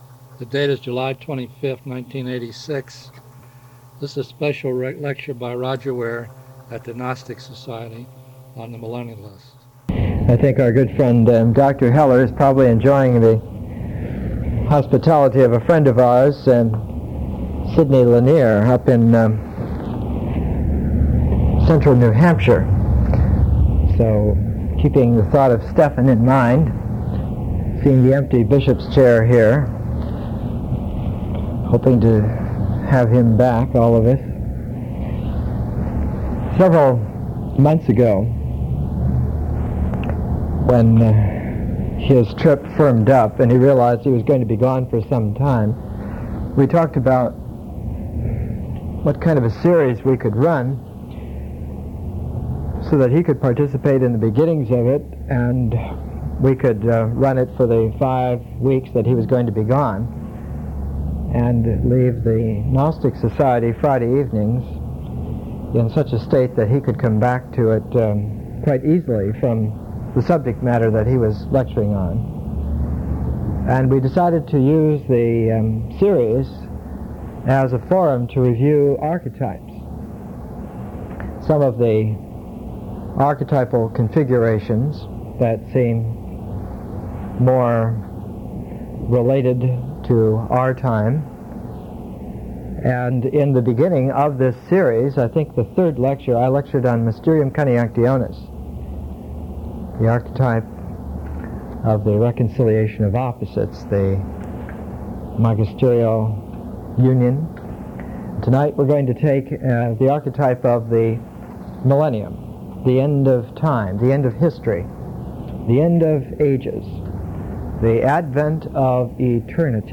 Presentations are 1-1.5 hours: an initial session of 30-45 minutes, an intermission for discussion or contemplation, and a second 30-45 minute session.